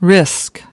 25. risk (n) /rɪsk/: sự rủi ro, nguy cơ